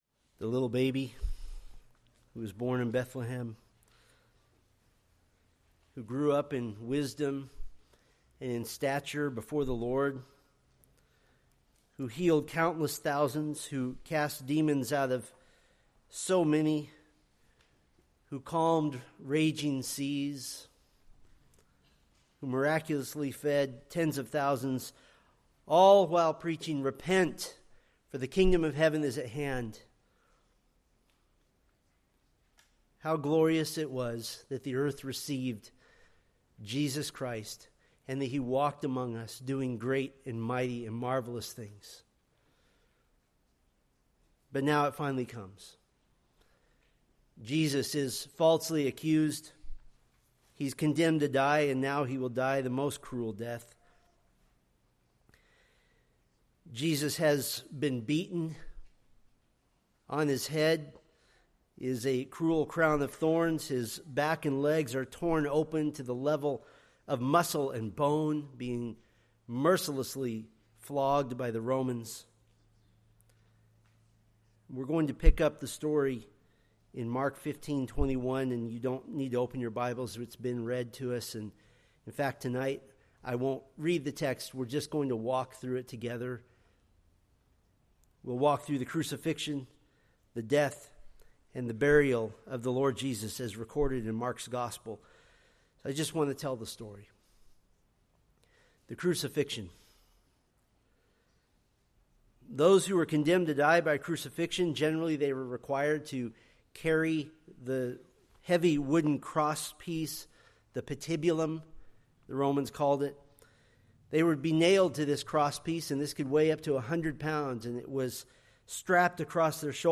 Preached April 18, 2025 from Mark 15:21-47